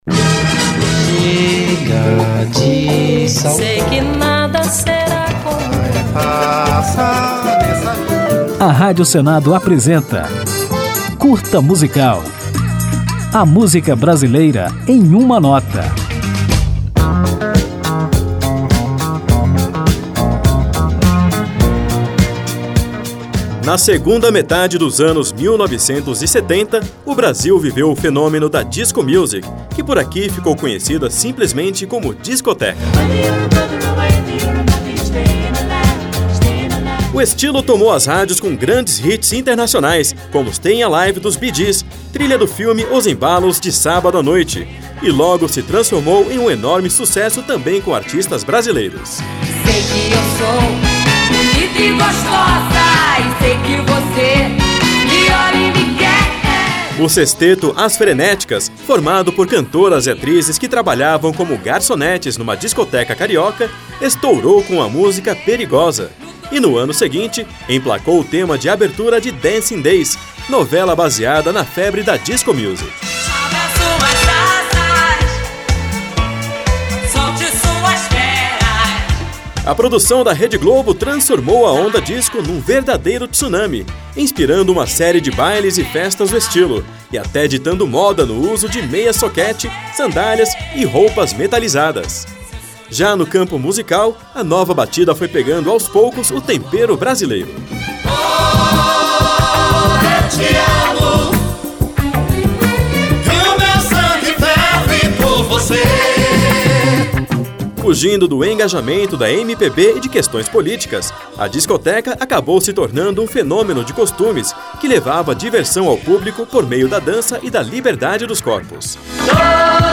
Neste Curta Musical você vai conferir como o ritmo conquistou o público e influenciou artistas nacionais e ainda vai ouvir a música A Noite Vai Chegar, um dos maiores hits da Disco Music brasileira.